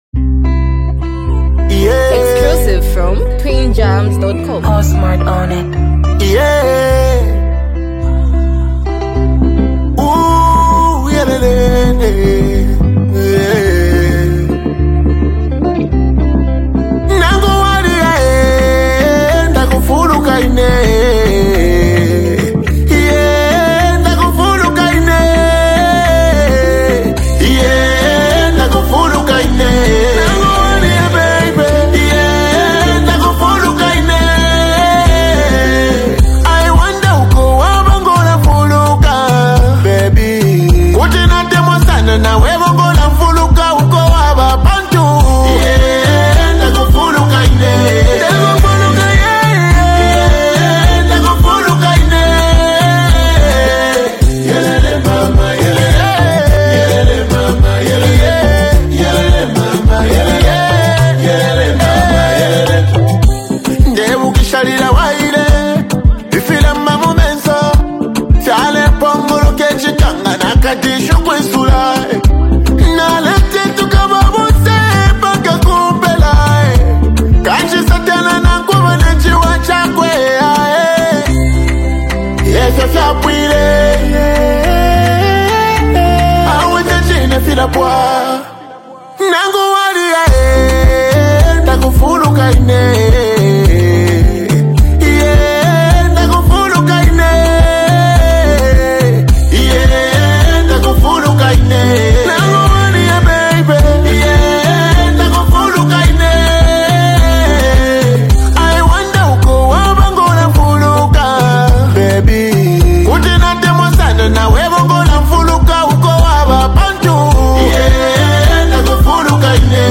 is an emotionally charged song